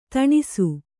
♪ taṇisu